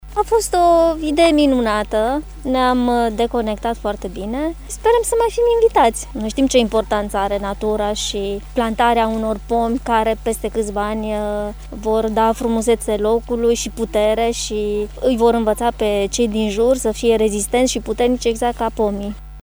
Voluntarii s-au declarat mulțumiți de scopul și finalitatea acțiunii: